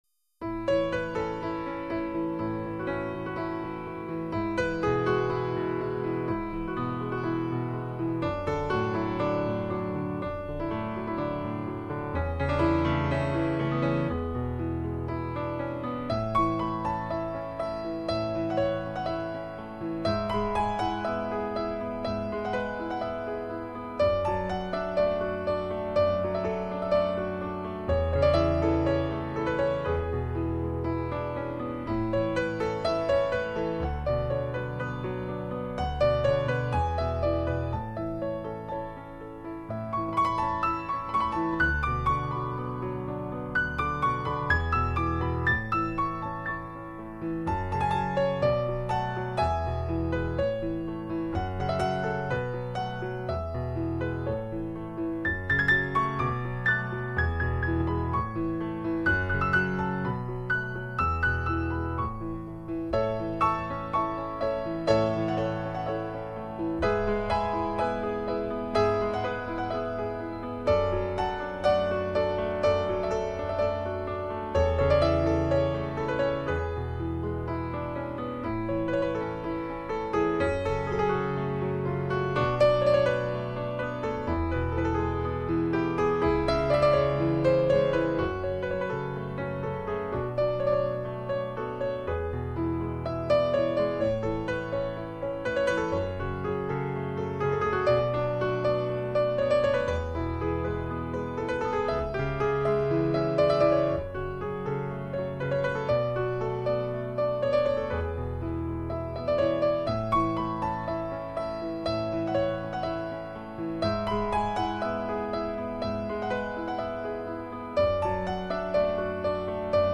新世紀古典動感鋼琴曲
随着琴鍵的起落、柔揚的旋律，使我們緬懷二三十年代裹洋場、異國情懷、纸醉金迷、繁華如夢的上海。